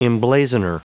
Prononciation du mot emblazoner en anglais (fichier audio)
Prononciation du mot : emblazoner